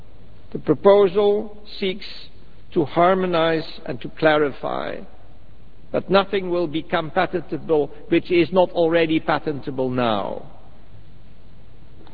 El Comisario Europeo